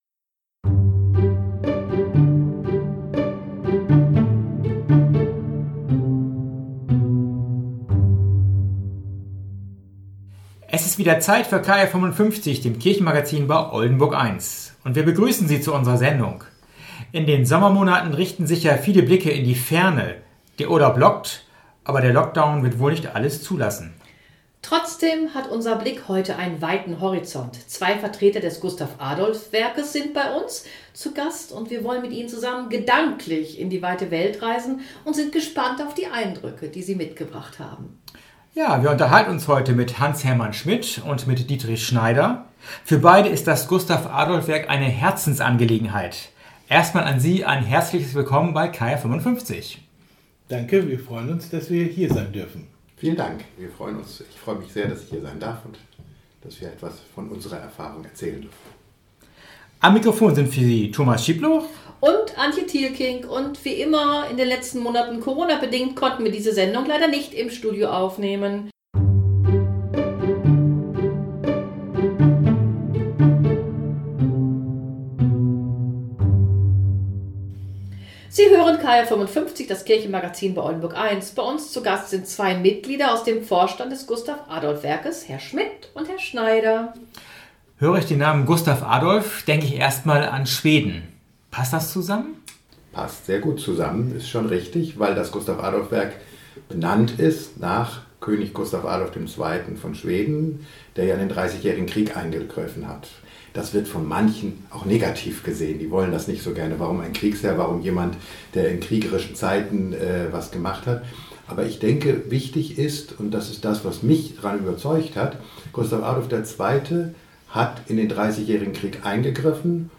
Geistlicher Snack: Slowenien